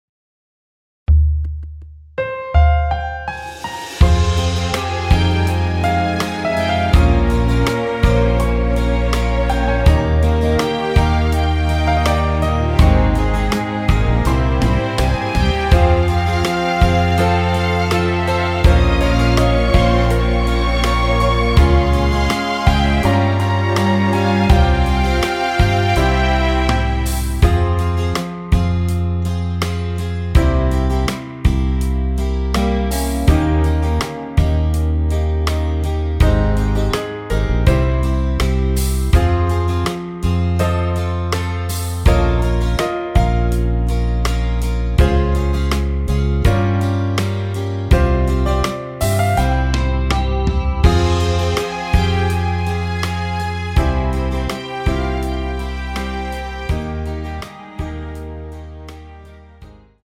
원키에서(+3)올린 MR입니다.
Fm
앞부분30초, 뒷부분30초씩 편집해서 올려 드리고 있습니다.
중간에 음이 끈어지고 다시 나오는 이유는